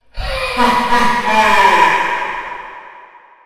Evil Laugh
cackle echo evil funny gamer horror joker laugh sound effect free sound royalty free Funny